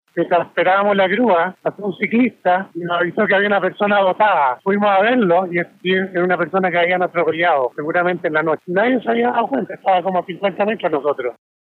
El individuo, que llamó a La Radio para relatar lo ocurrido, comentó que cuando esperaba la llegada de la grúa, un ciclista que pasaba por el lugar le advirtió que unos metros más allá había un cuerpo, esto a un costado de las barreras de contención.
060-cu-testigo-muerto-atropello-acceso-sur.mp3